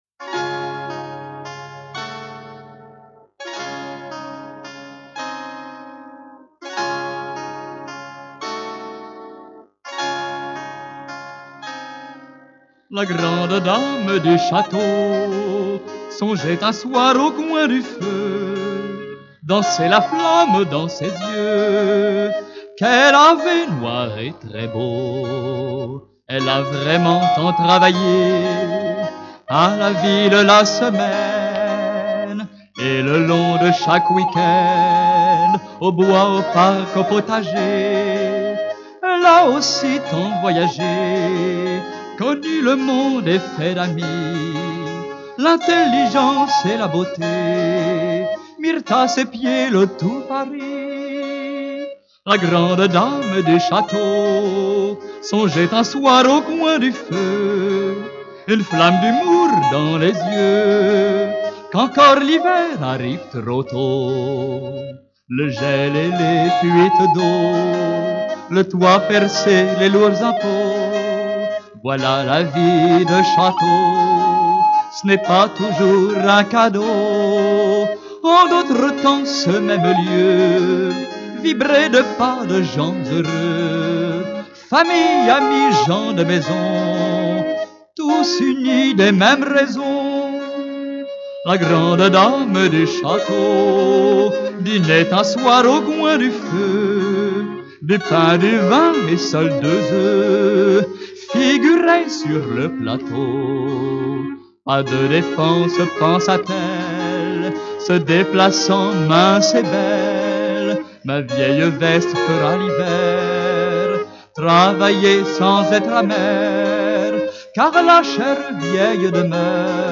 Enregistré à Valotte
Clavinet